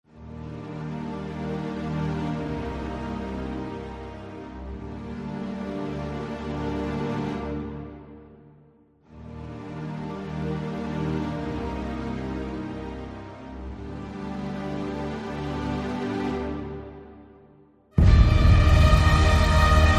# cinematic # buildup # epic